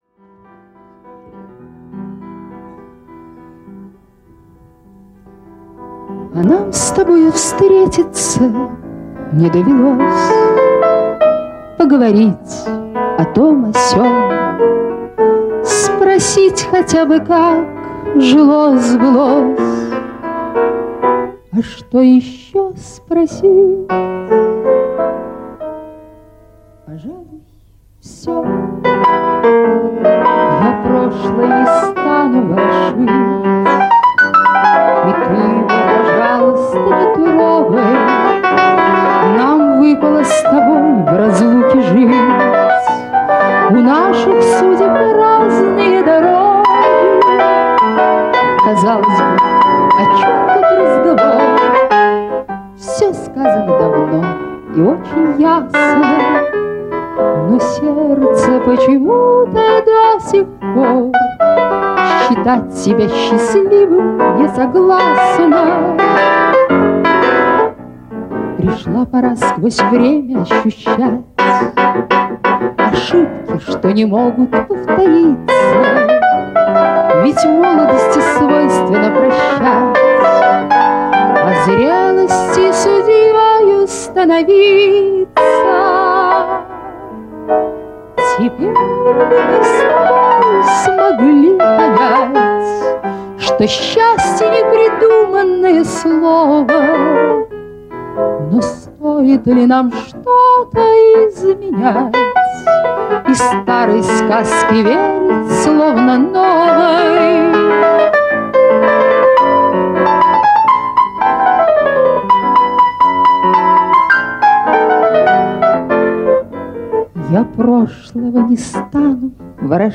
рояль